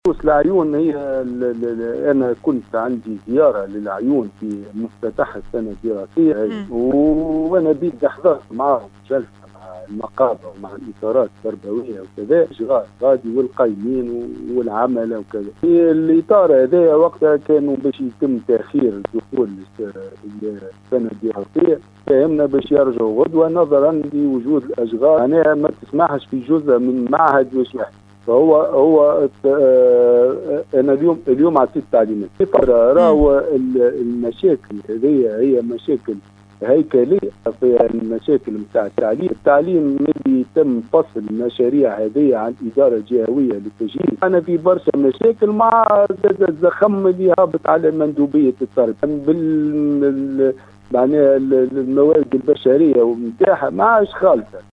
وفي علاقة بهذه الوقفات الاحتجاجية ،أكد والي الجهة محمد سمشة  خلال برنامج “نهارك زين ” انه وفي ما يخص إشكال التيار الكهربائي بالعيون أكد انه أعطى التعليمات بالتنقل إلى الجهة وحل الإشكال القائم .